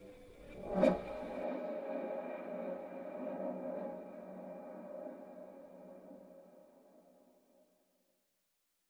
Reverse-Forward Clonk